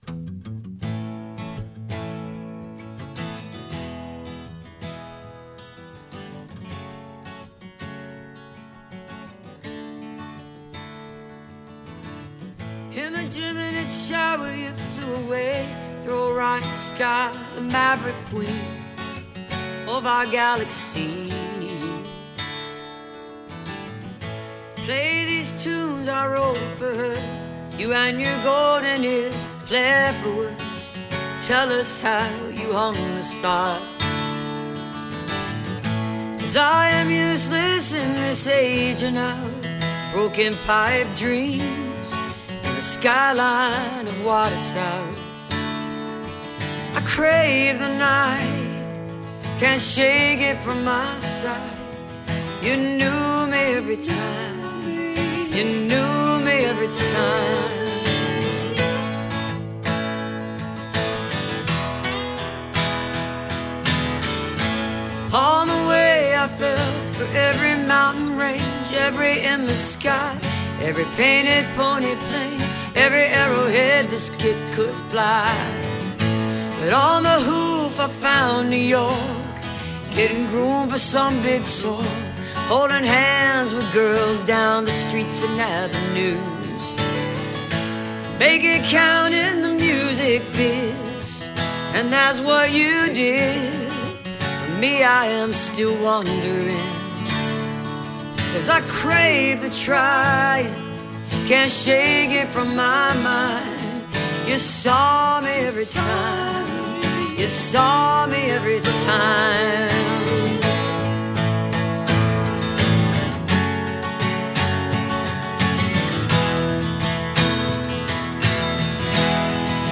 (captured from webcast)
(album version)